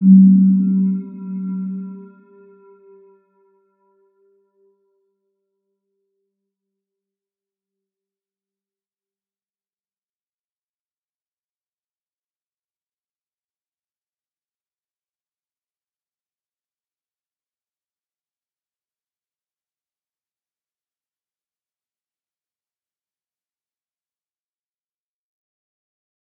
Round-Bell-G3-p.wav